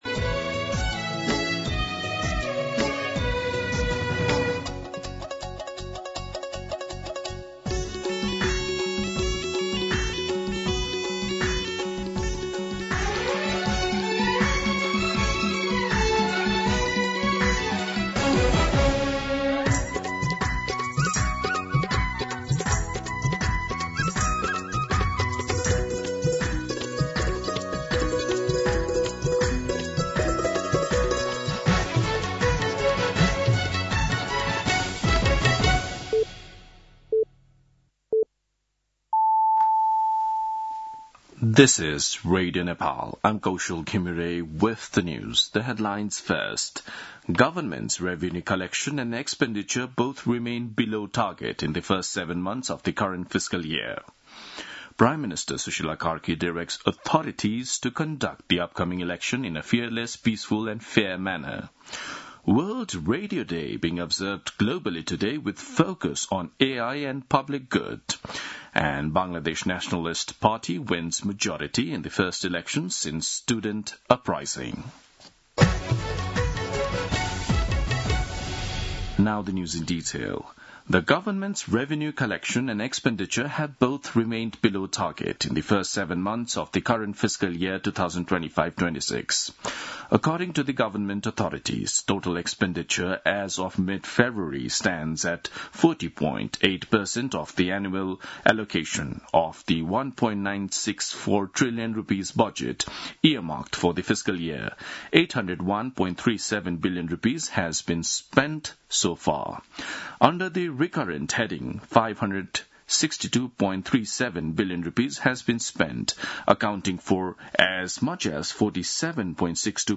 दिउँसो २ बजेको अङ्ग्रेजी समाचार : १ फागुन , २०८२